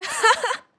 sura_cheer1.wav